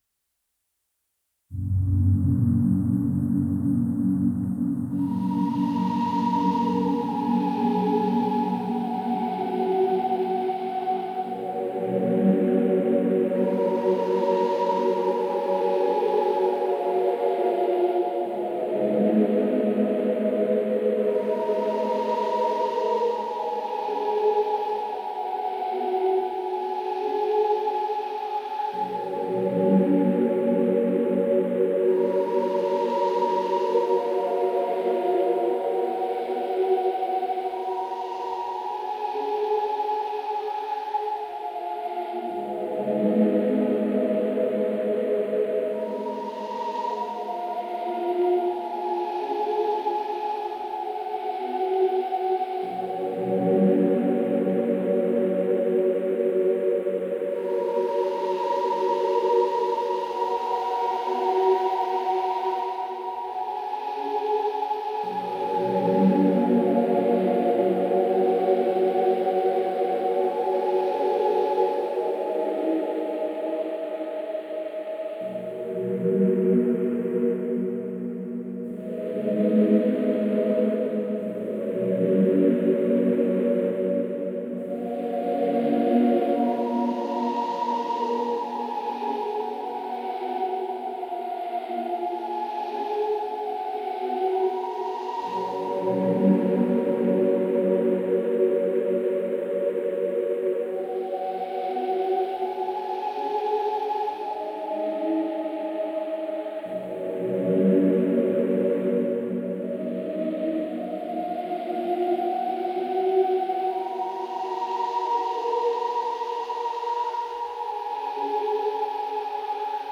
Зарисовочка атмосферного вступления